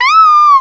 cry_not_stufful.aif